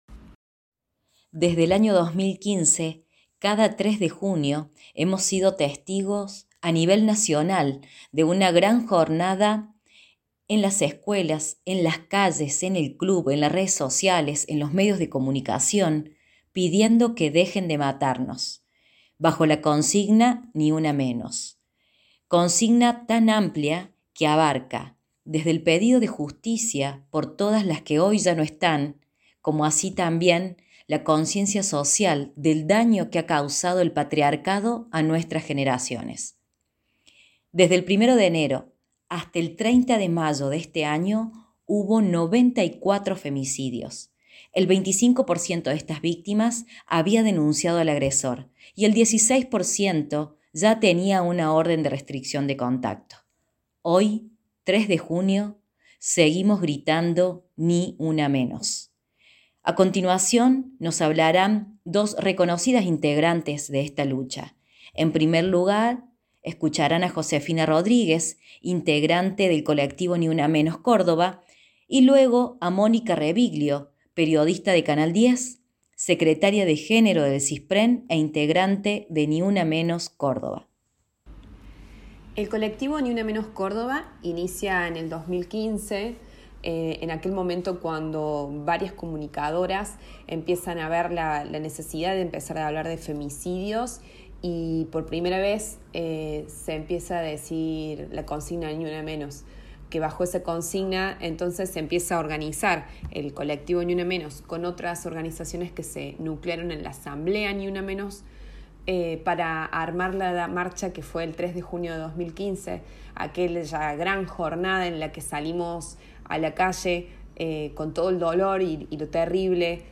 En el audio podrán escuchar a dos activistas pertenecientes a “Ni Una Menos Córdoba”.